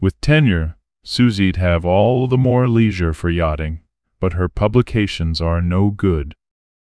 multilingual multilingual-tts text-to-speech voice-cloning